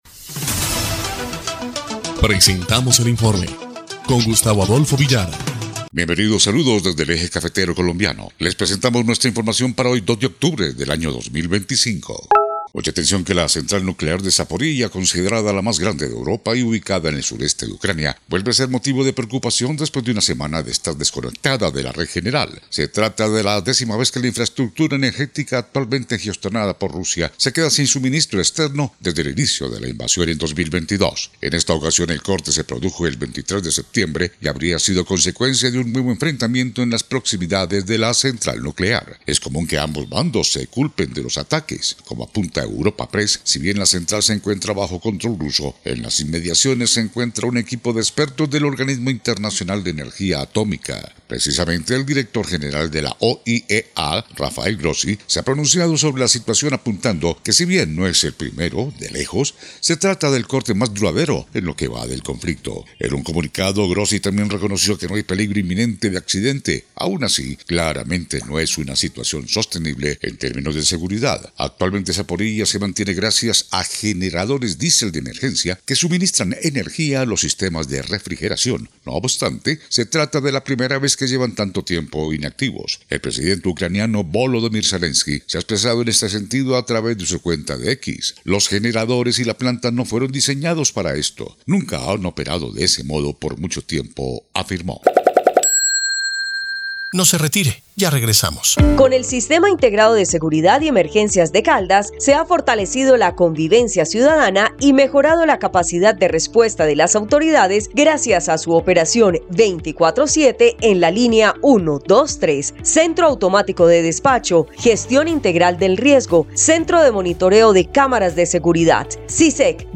EL INFORME 2° Clip de Noticias del 2 de octubre de 2025